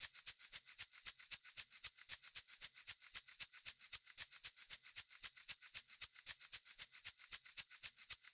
01 Shaker.wav